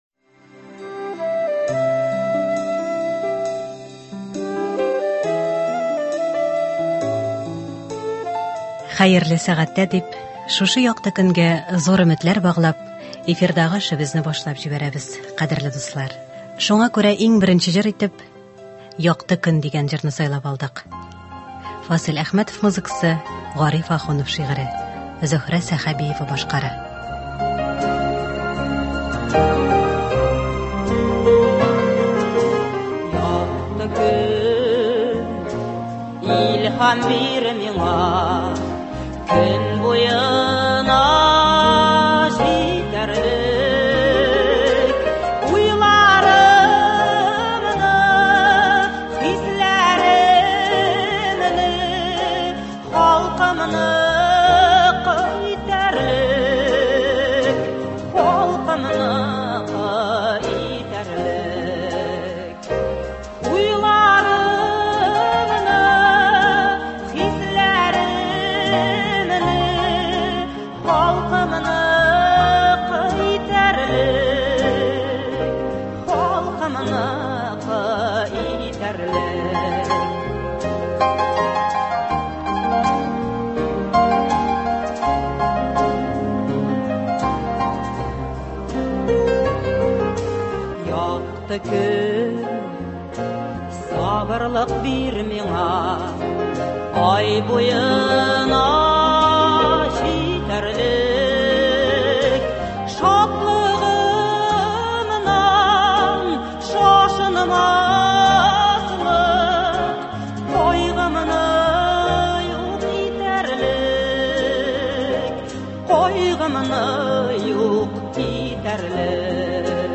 Фарсель Зыятдинов әсәрләреннән әдәби-музыкаль композиция.